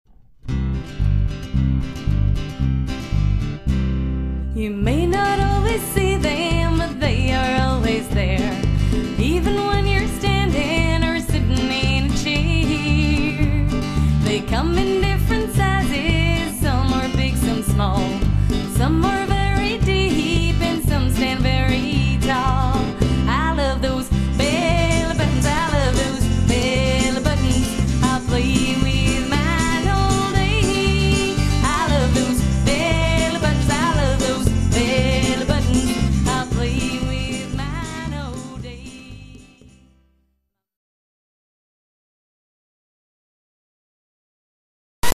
catchy children's songs